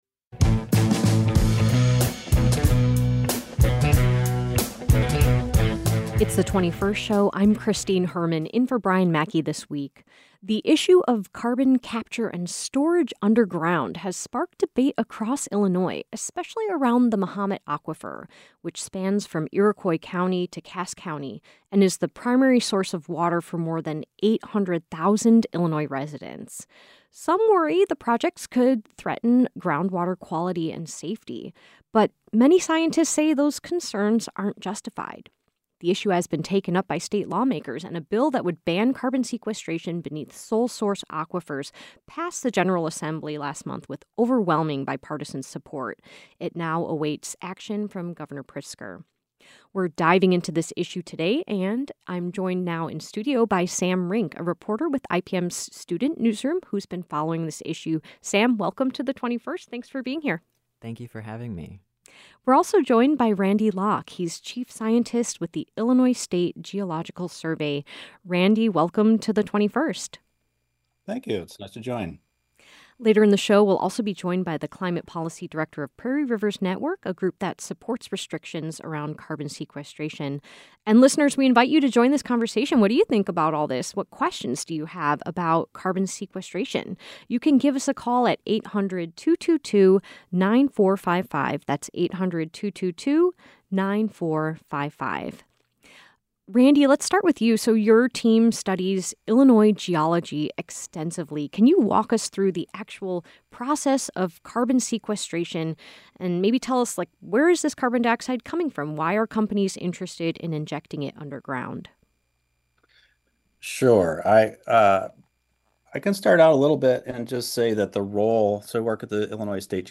A student reporter who has been following the issue, a geological expert and a climate policy expert join the program.